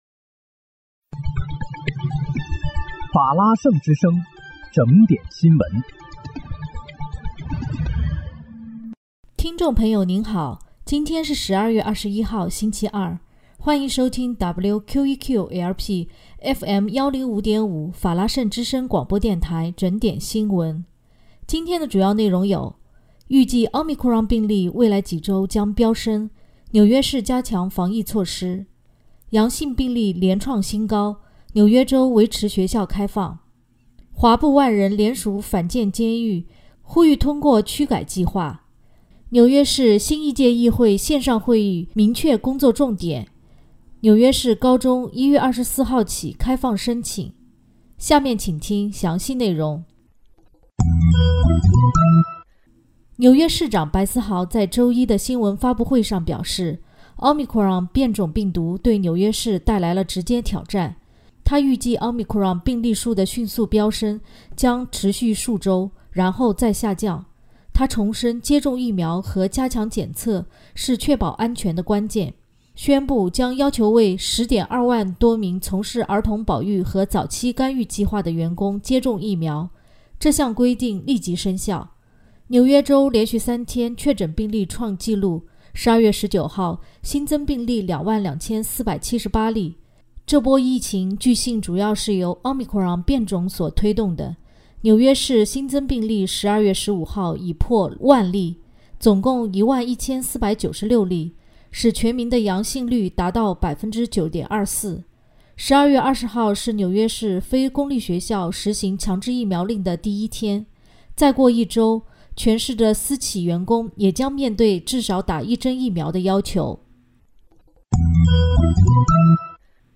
12月21日（星期二）纽约整点新闻
听众朋友您好！今天是12月21号，星期二，欢迎收听WQEQ-LP FM105.5法拉盛之声广播电台整点新闻。